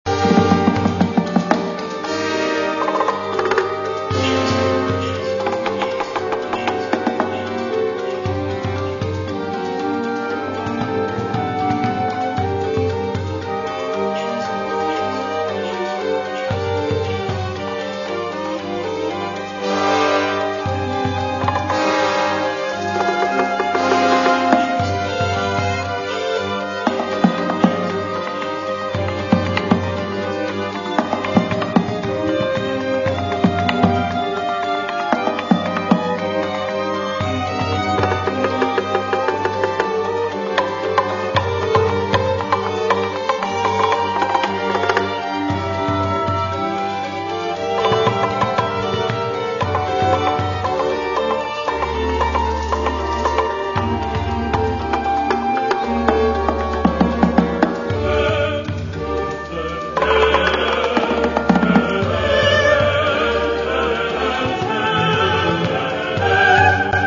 Bach, Westerse componist uit de barok